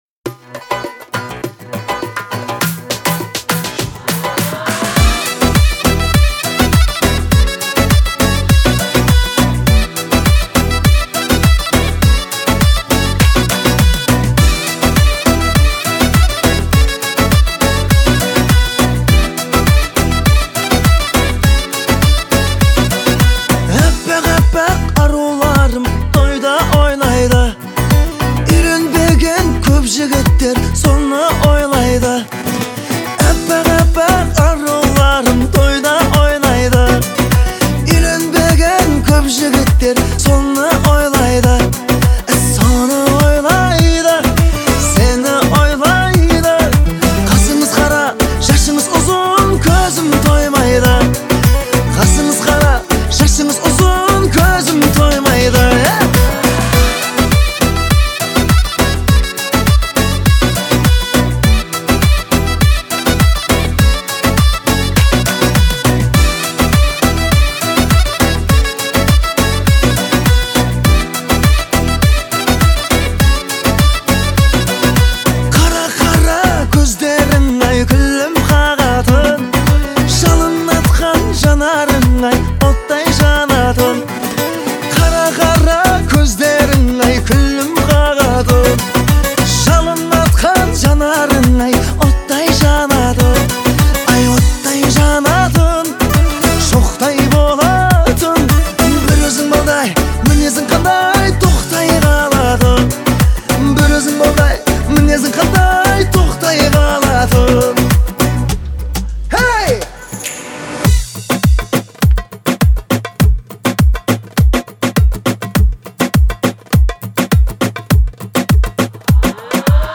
относится к жанру поп и обладает романтическим настроением